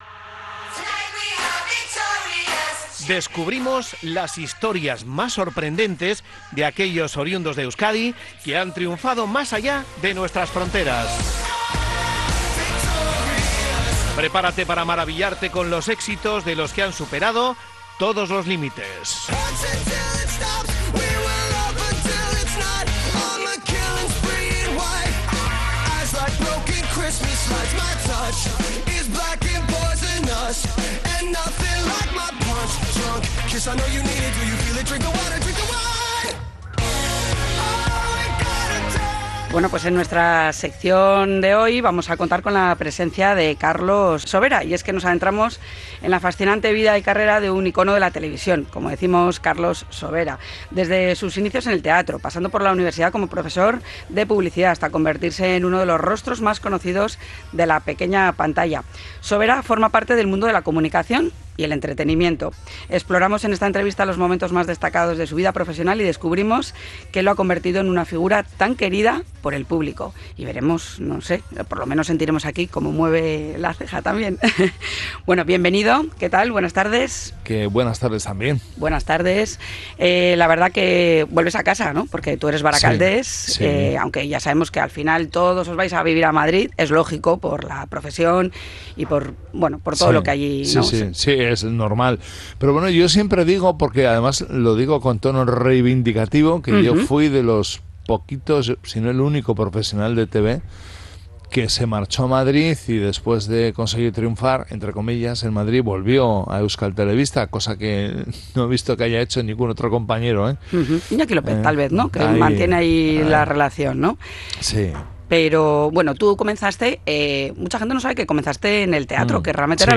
Carlos Sobera un reconocido presentador de televisión, actor y empresario teatral vizcayno, viene a nuestro programa de tarde en Onda Vasca